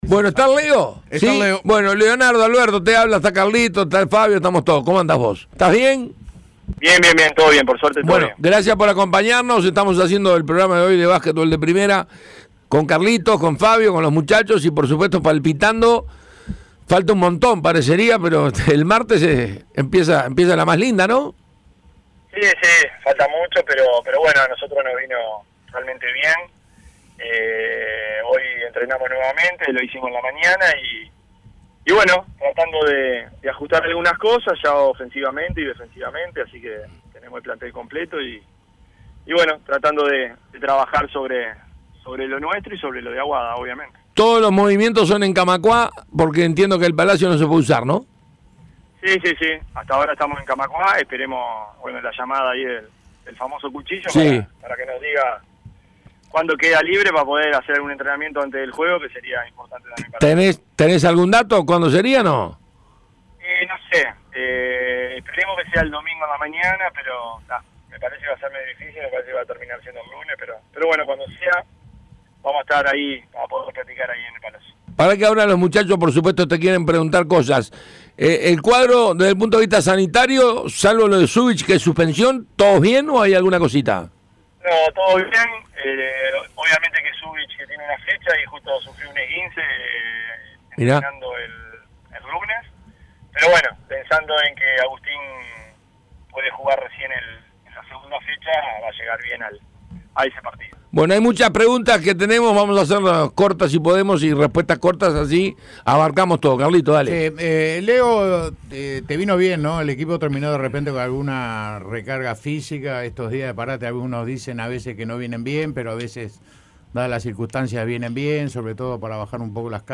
Escuchá la entrevista completa donde cuenta cómo se preparan y qué puede suceder a partir del 9 de mayo.